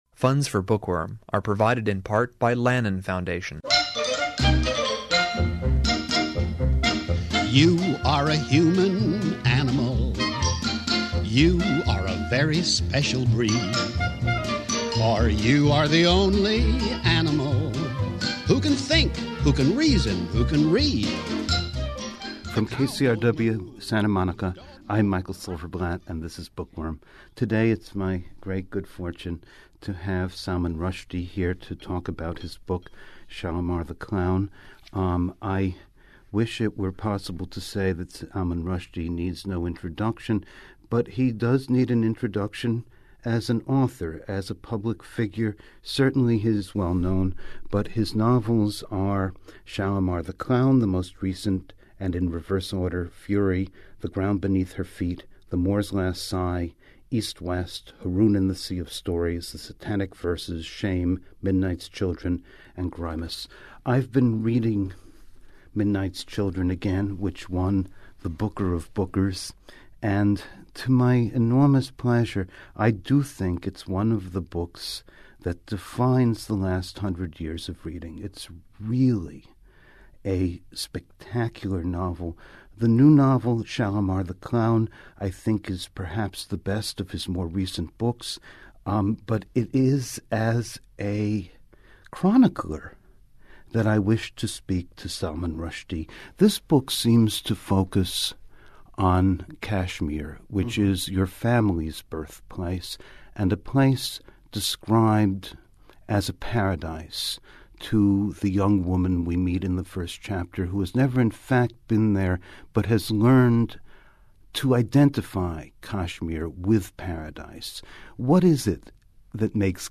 In this conversation, he describes the ways in which an historical conflict can determine the course of love.